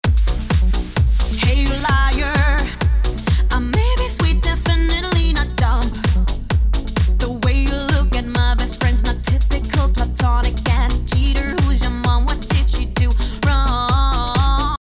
Dieser Loop stammt aus Ueberschall Vocal Dance Hits 2: